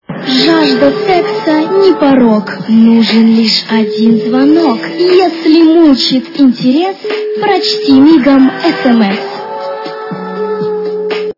» Звуки » звуки для СМС » Звонок для СМС - Если мучит интерес-прочти мигом СМС!
При прослушивании Звонок для СМС - Если мучит интерес-прочти мигом СМС! качество понижено и присутствуют гудки.